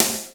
DANCE SD 3.wav